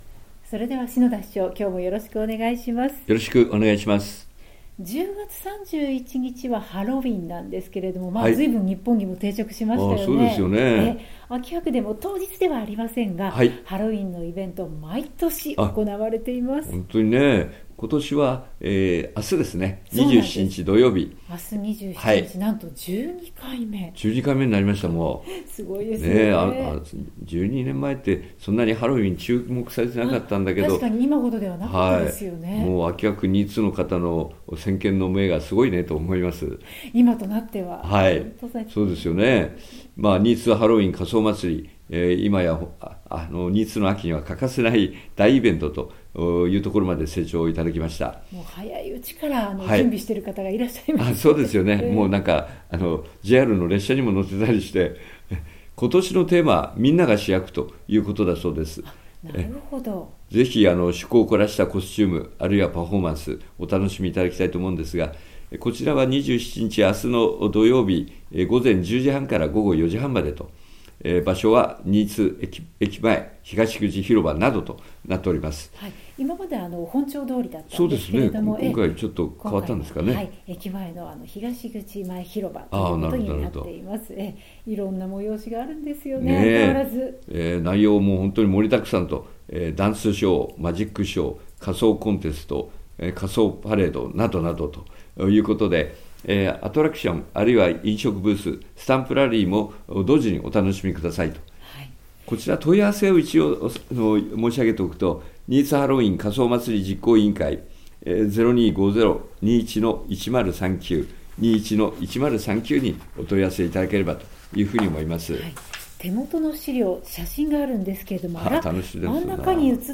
篠田市長の青空トーク | RADIOCHAT76.1MHz